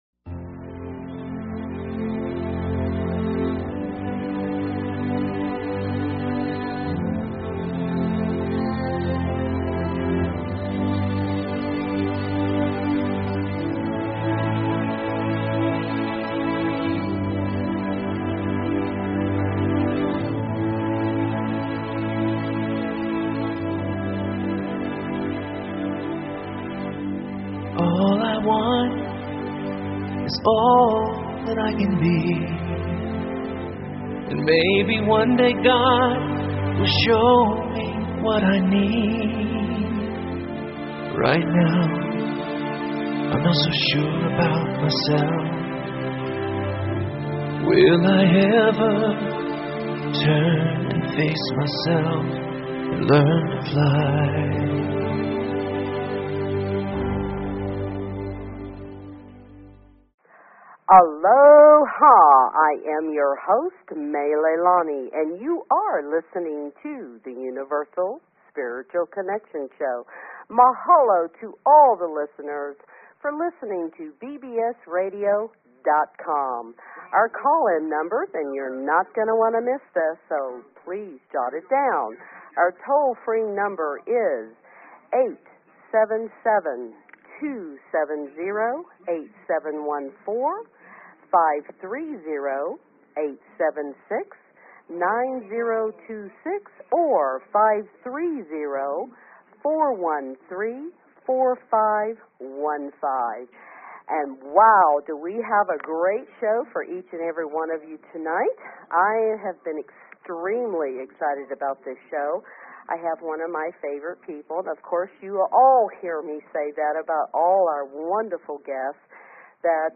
Talk Show Episode, Audio Podcast, Universal_Spiritual_Connection and Courtesy of BBS Radio on , show guests , about , categorized as
This show is Fun and opens up a whole new world of exciting topics and "Welcomes" your Questions and Comments. The show explores a variety of subjects from the Spiritual Eastern, Western and Native American Indian beliefs to the basic concepts of Love and Success.